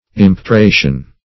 Impetration \Im`pe*tra"tion\, n. [L. impetratio: cf. F.